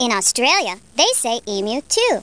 00964_Sound_Emu.Australia.mp3